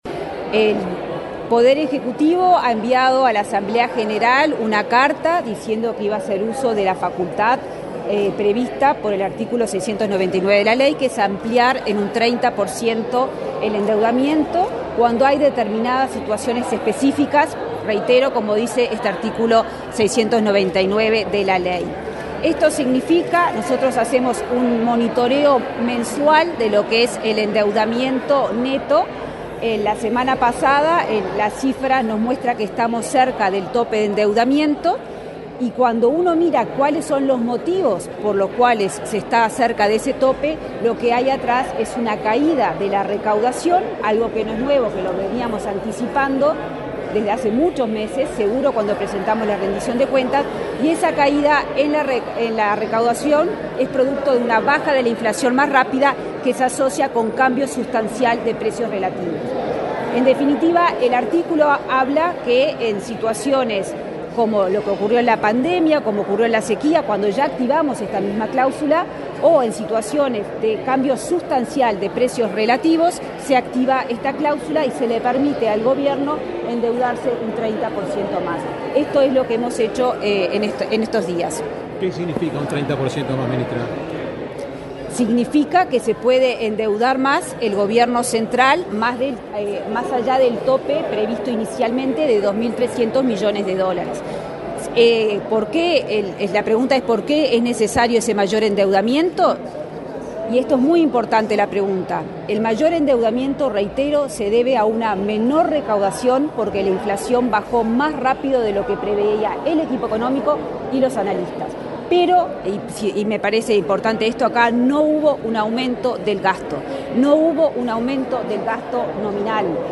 Declaraciones de la ministra de Economía, Azucena Arbeleche
Este martes 17 en la Torre Ejecutiva, la ministra de Economía, Azucena Arbeleche, dialogó con la prensa, luego de participar en la presentación de la